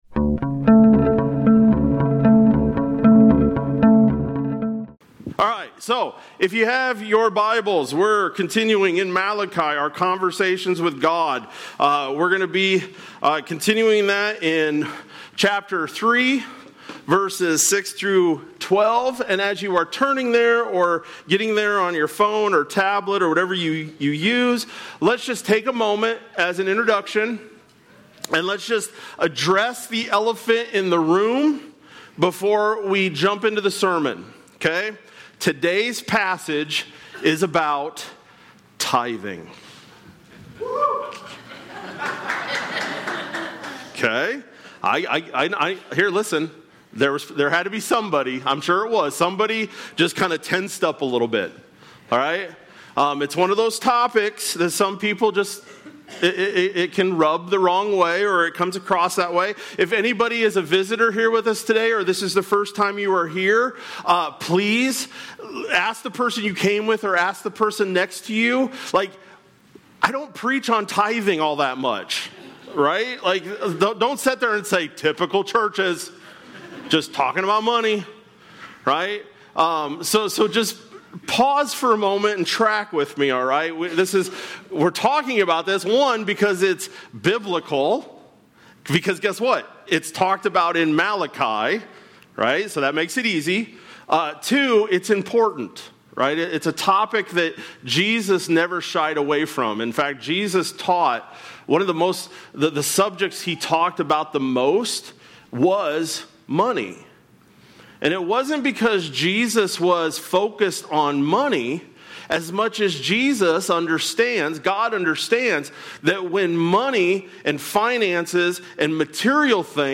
July-20-Sermon-Audio.mp3